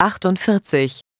ich habe mal Sprachausgaben für eine Sekunde erstellt sie Anhang .